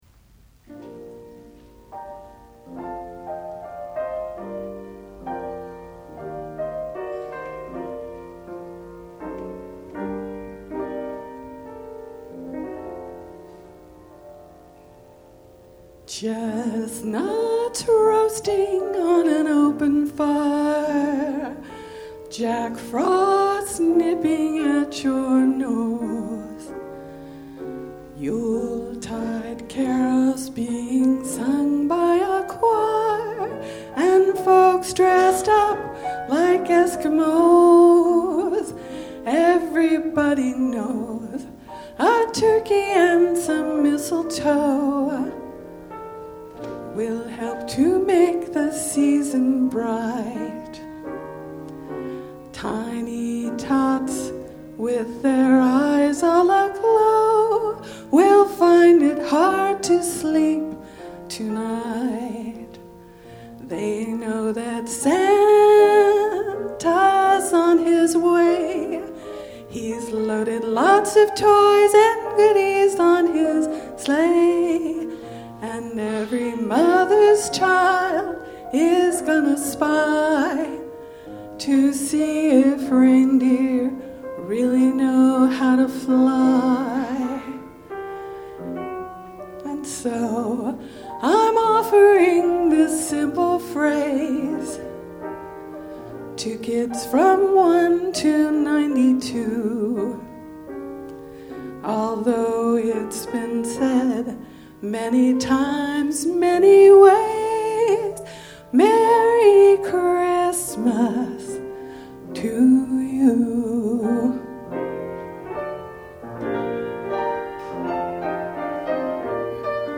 Center for Spiritual Living, Fremont, CA
2009 Winter Concert, Wednesday, December 16, 2009